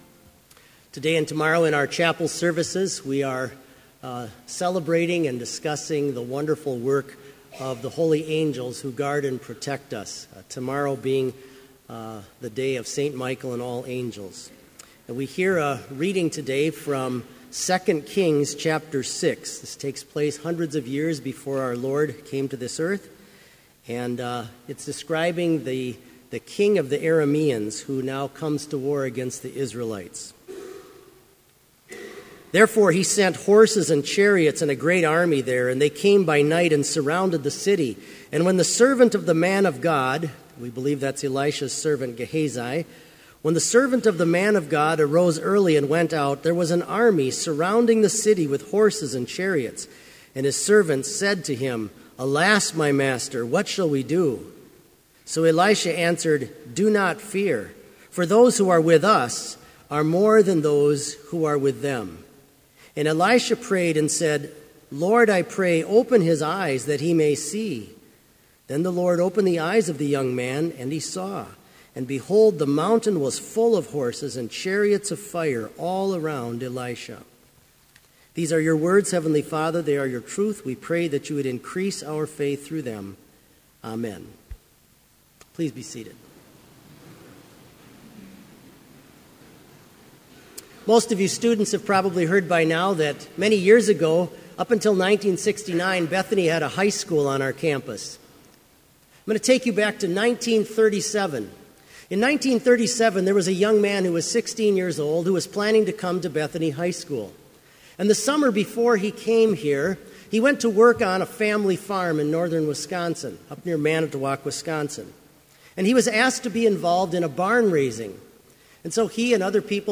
Complete Service
• Prelude
• Devotion
• Postlude
This Chapel Service was held in Trinity Chapel at Bethany Lutheran College on Monday, September 28, 2015, at 10 a.m. Page and hymn numbers are from the Evangelical Lutheran Hymnary.